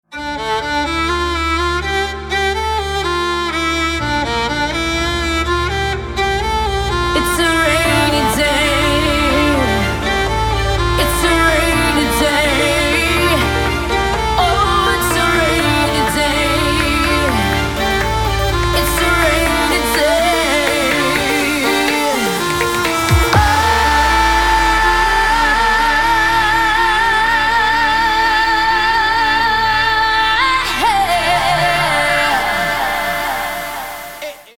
• Качество: 224, Stereo
женский вокал
dance
спокойные
скрипка